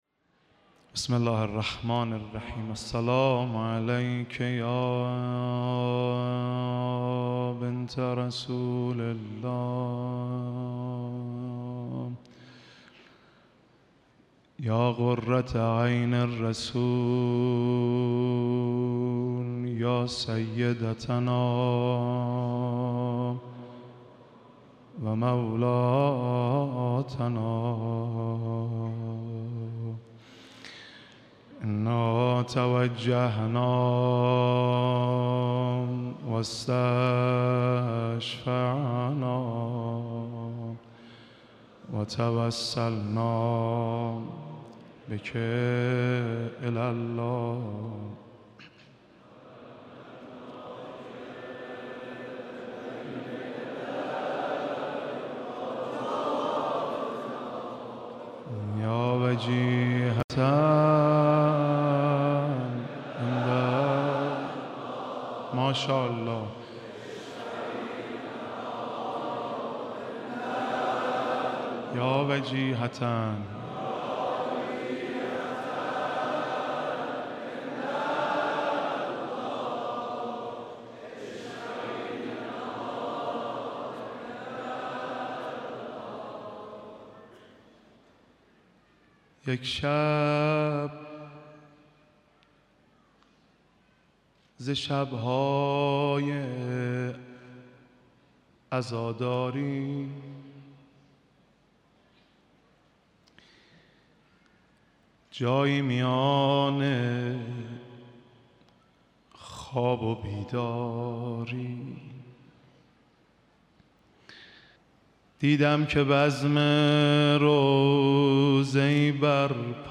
آخرین شب مراسم عزاداری حضرت فاطمه‌زهرا سلام‌الله‌علیها در حسینیه امام خمینی(ره)
روضه‌خوانی و نوحه‌خوانی